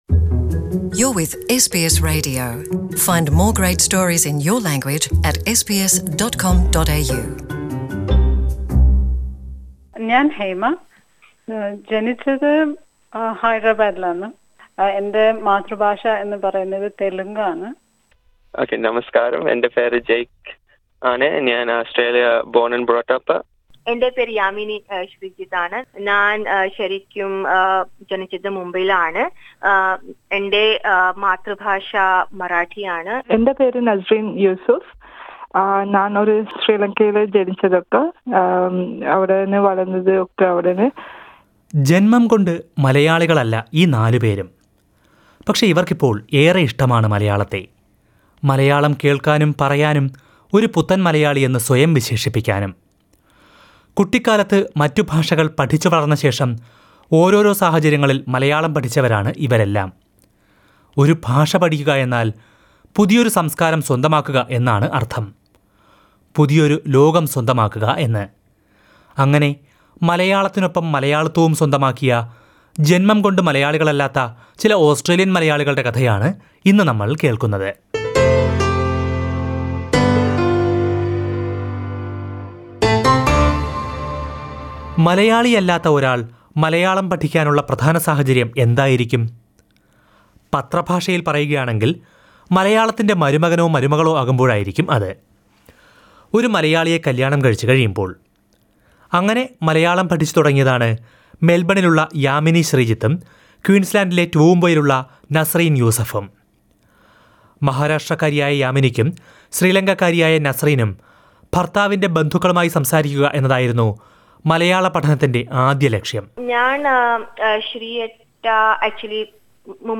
ഒരു ഭാഷ പഠിക്കുമ്പോൾ പുതിയൊരു സംസ്കാരവും പുതിയൊരു ലോകവും കൂടിയാണ് സ്വന്തമാകുന്നത്. മലയാളികളല്ലാതിരുന്നിട്ടും മലയാളം പഠിച്ച, സംസാരിക്കുന്ന ചിലരുടെ കഥകൾ കേൾക്കാം...